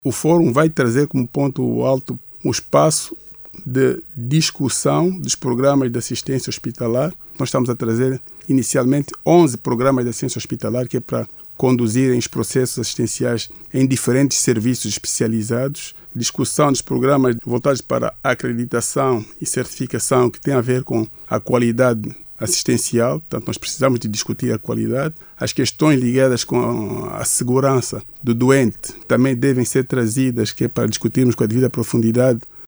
O diretor nacional dos hospitais, Benedito Quintas, diz que o certame vai servir também para a definição de políticas públicas viradas à assistência hospitalar de melhor qualidade e uma gestão diferenciada.